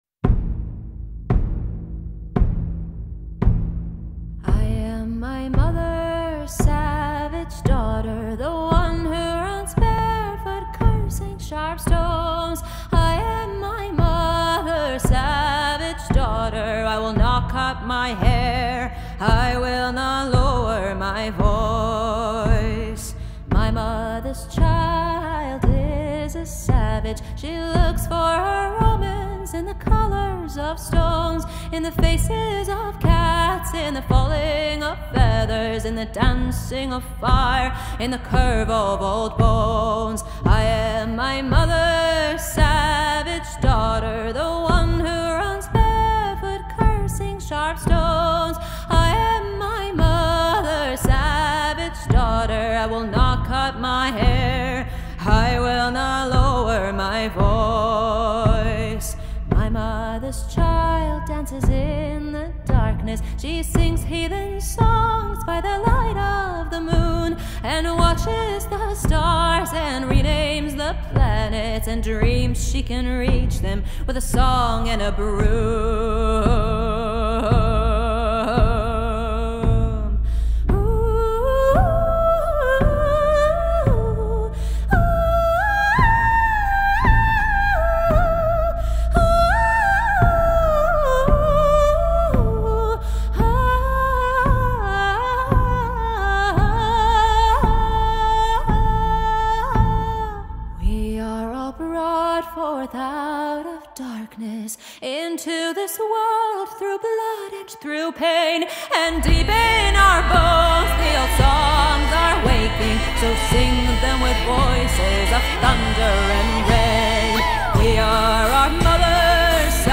یه آهنگ حماسی
کاور آهنگی